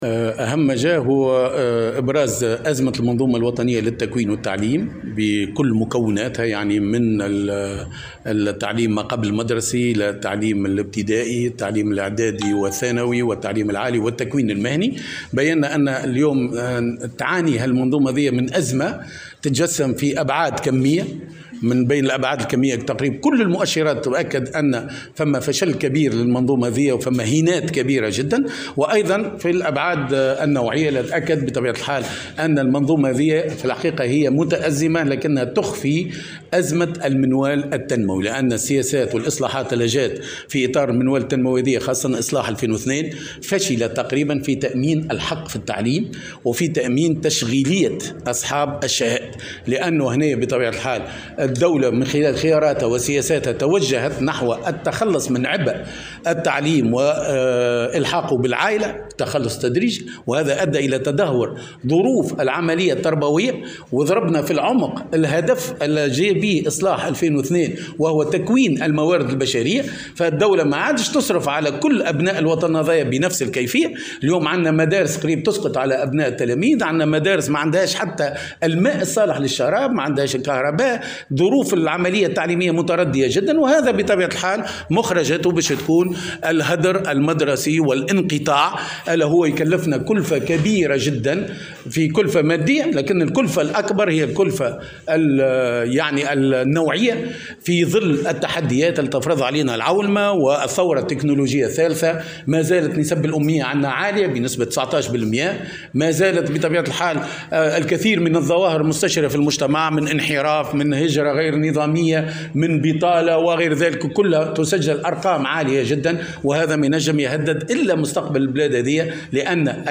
خلال ندوة صحفية عُقدت اليوم الخميس